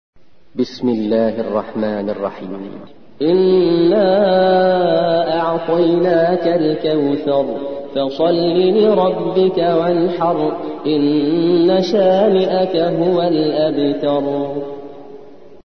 108. سورة الكوثر / القارئ